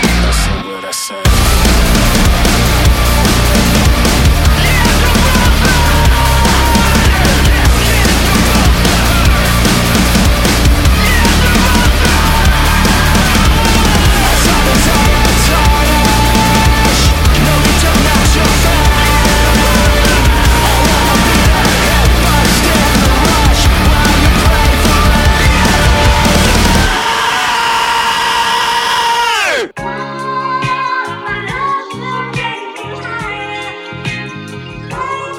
menggabungkan kekuatan sonik punk, elektronik, dan hardcore
suara ambisius